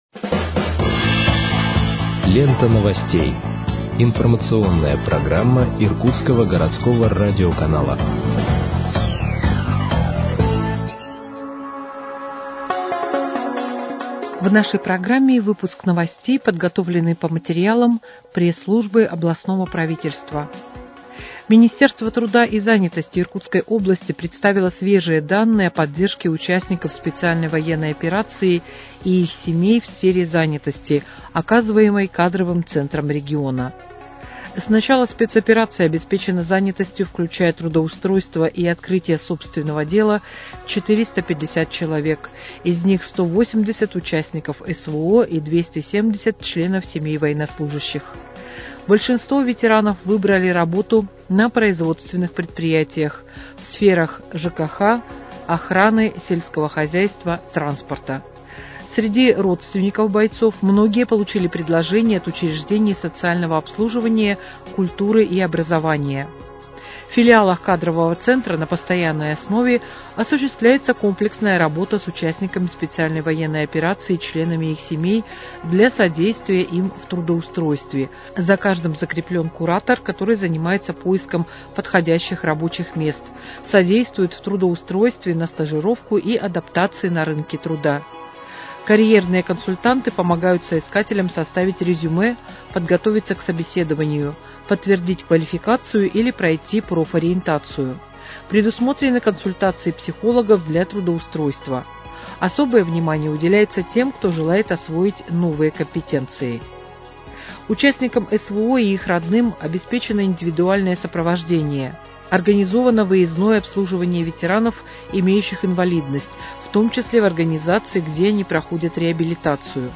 Выпуск новостей в подкастах газеты «Иркутск» от 4.08.2025 № 1